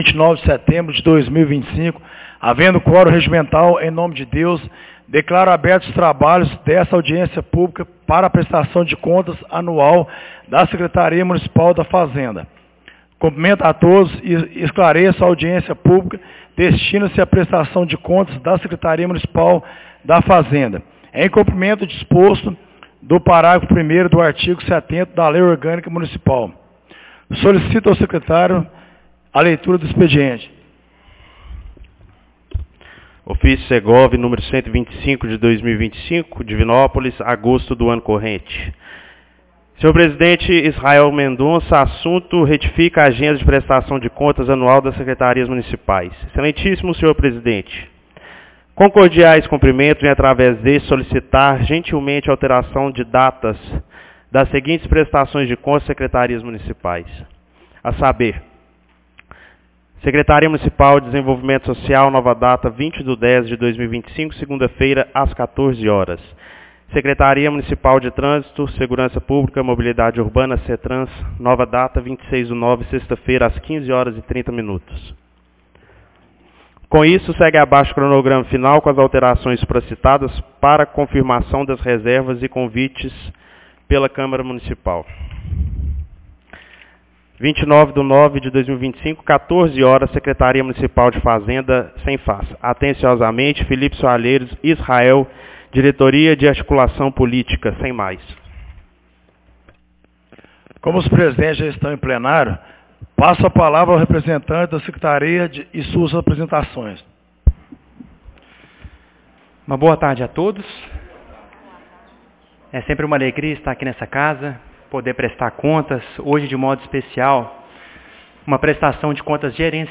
Audiencia publica Prestação de Contas Secretaria de Fazenda 29 de setembro de 2025